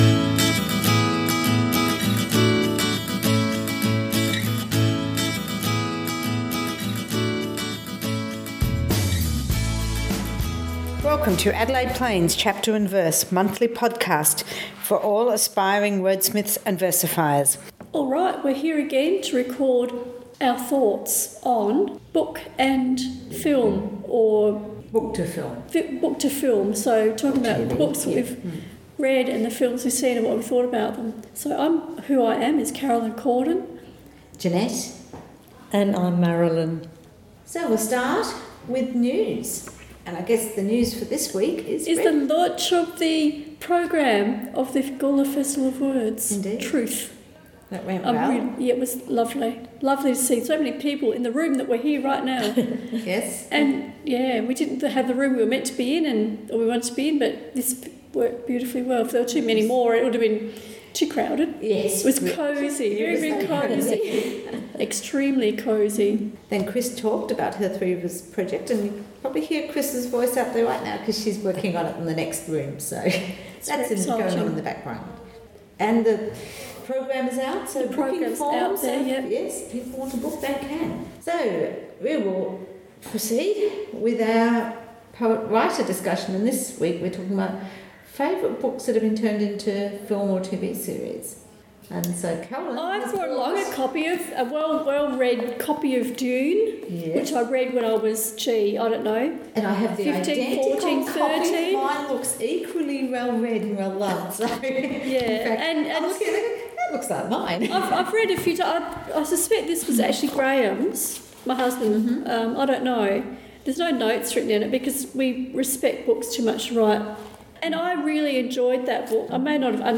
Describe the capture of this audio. Adelaide Plains Chapter and Verse monthly podcast recorded Thursday 31st May at the P/A Hotel, Murray St Gawler.